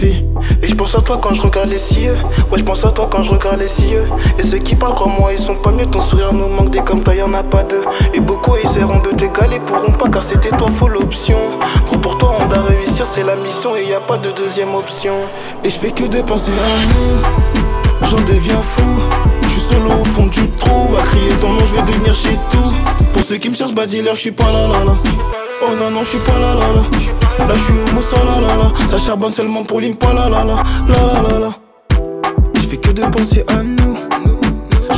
Hip-Hop Rap R B Soul
Жанр: Хип-Хоп / Рэп / R&B / Соул